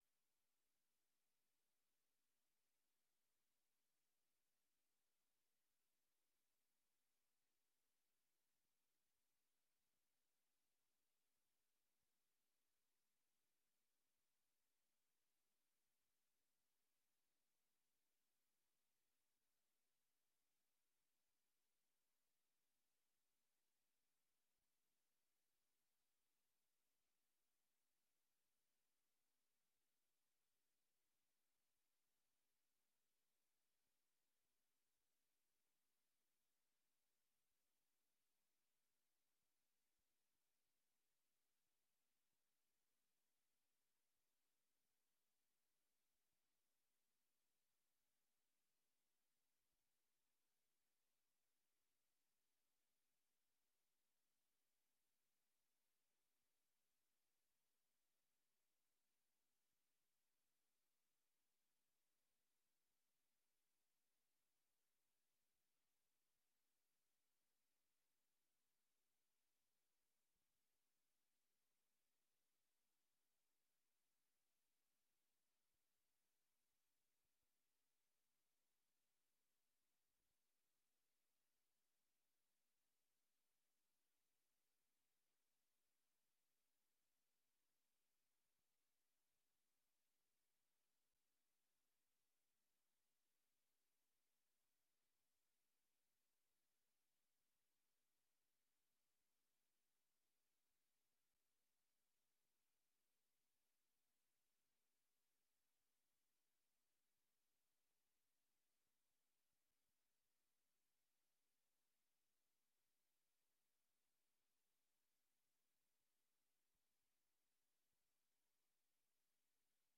Oordeelsvormende vergadering 09 februari 2023 20:45:00, Gemeente Dronten
Download de volledige audio van deze vergadering